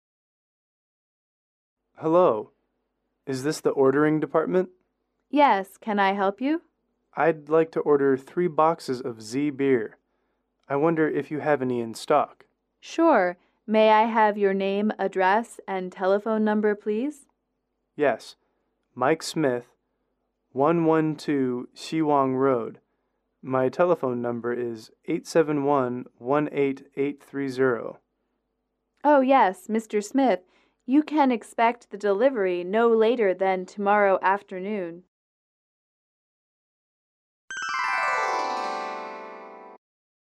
英语主题情景短对话59-3：订货（MP3）
英语口语情景短对话59-3：订货（MP3）